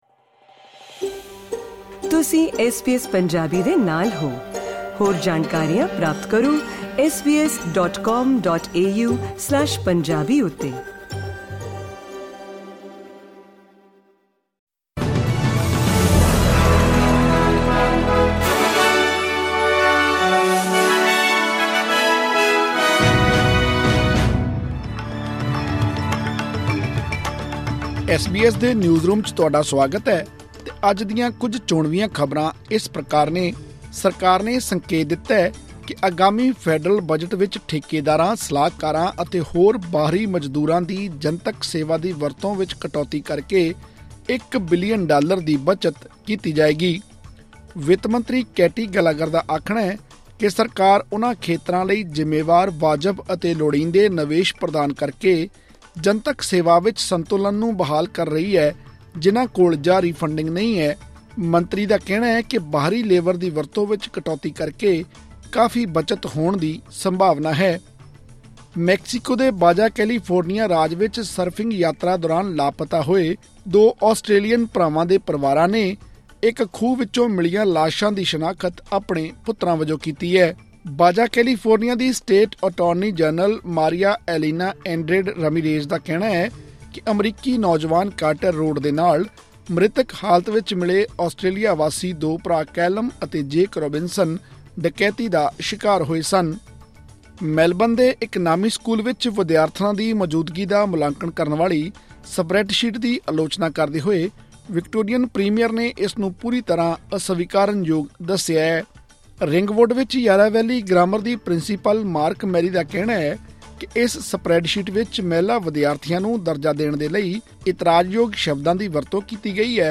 ਐਸ ਬੀ ਐਸ ਪੰਜਾਬੀ ਤੋਂ ਆਸਟ੍ਰੇਲੀਆ ਦੀਆਂ ਮੁੱਖ ਖ਼ਬਰਾਂ: 6 ਮਈ, 2024